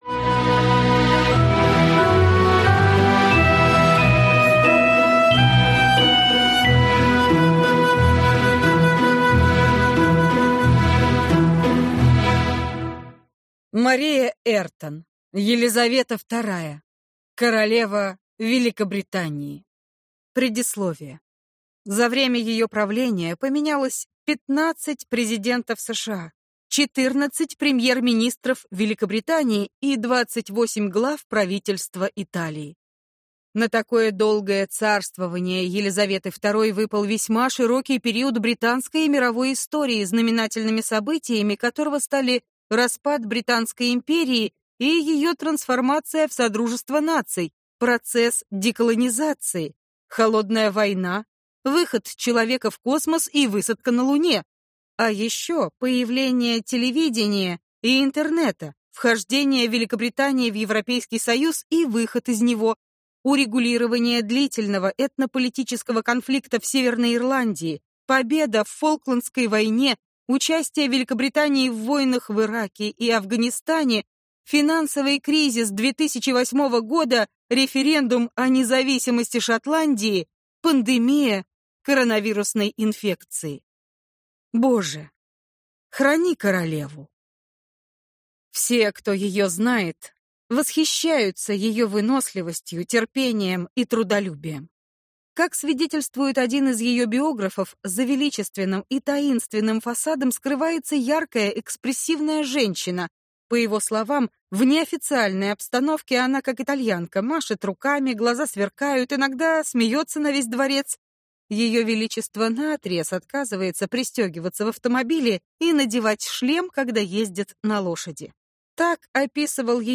Аудиокнига Елизавета II – королева Великобритании | Библиотека аудиокниг
Прослушать и бесплатно скачать фрагмент аудиокниги